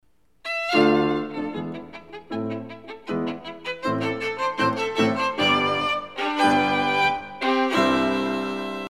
sonata.mp3